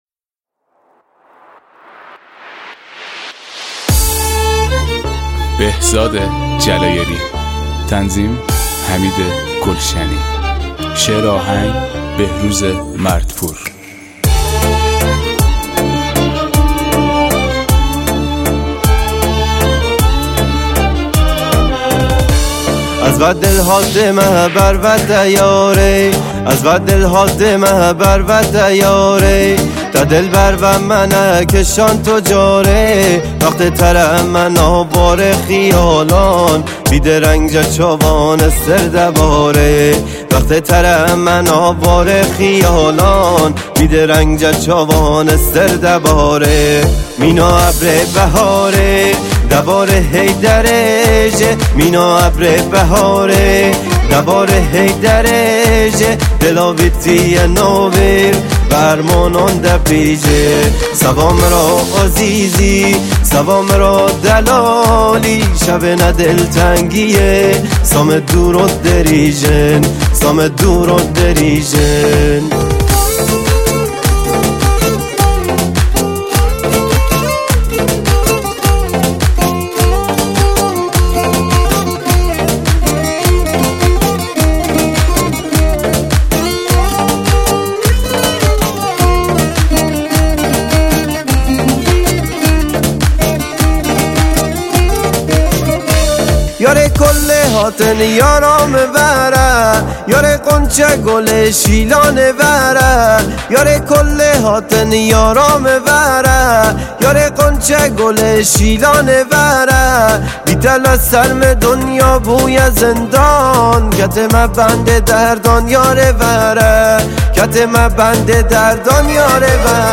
کرمانجی